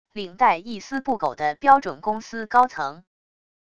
领带一丝不苟的标准公司高层wav音频